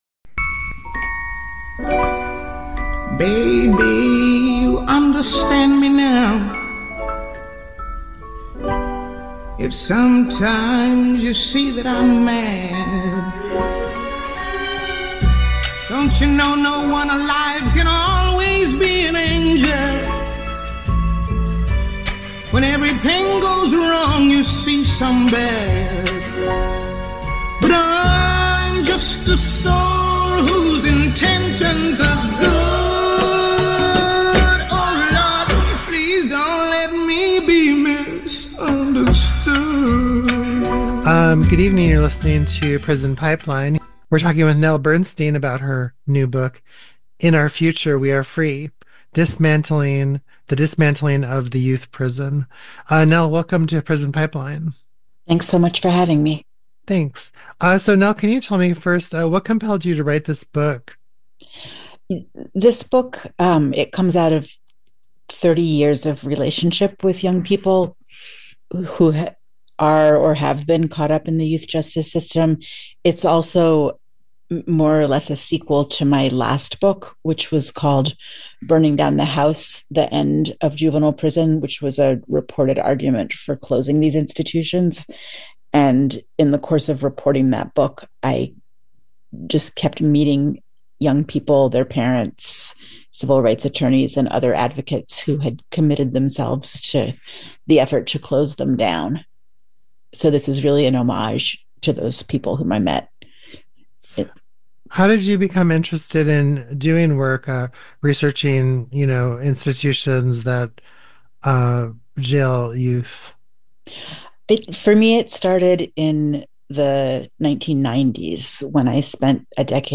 is joined in conversation by journalist